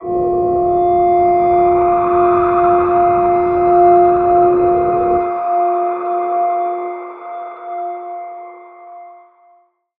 G_Crystal-F5-f.wav